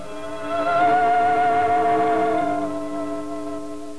a musical cue.